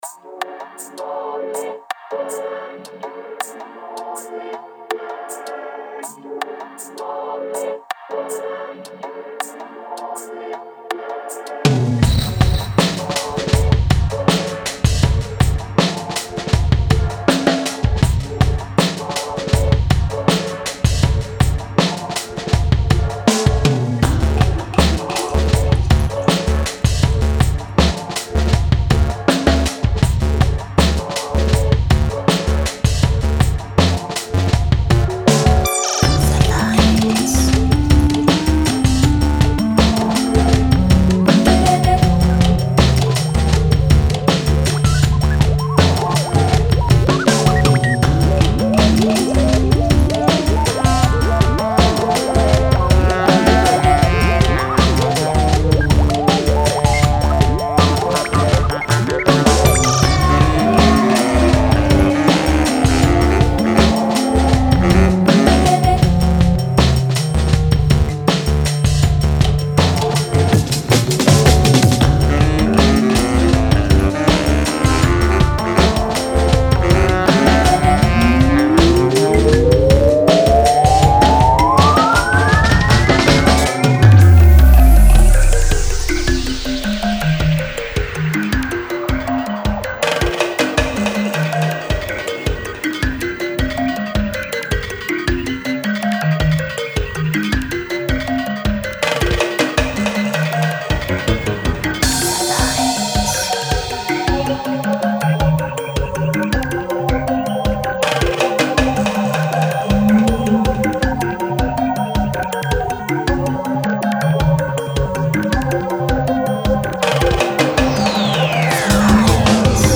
DOPEなやつを作ろうとしていた気がする。